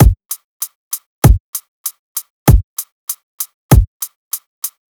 FK097BEAT2-L.wav